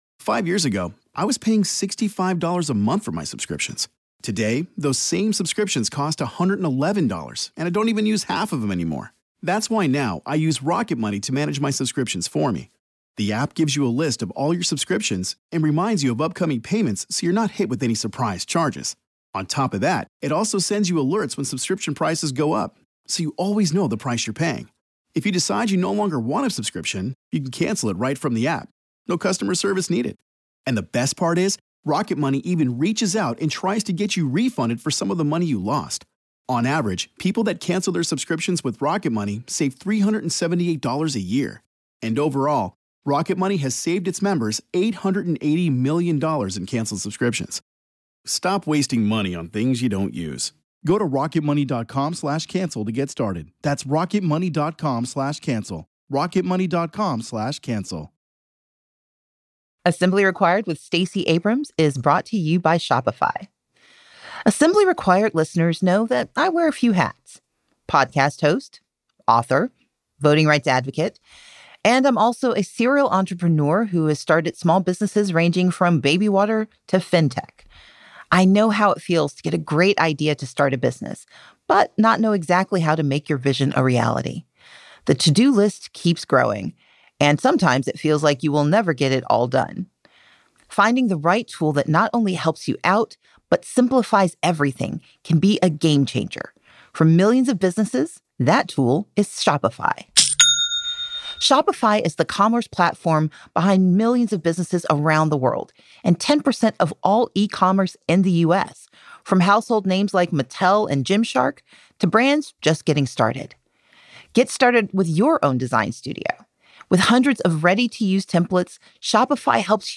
This week on Assembly Required, Stacey is joined by Adam Serwer, senior writer at The Atlantic and author of The Cruelty Is the Point, to unpack the Republican attack on knowledge, its real world impact, and explore what we can do to resist their attempts to replace truth with ideology.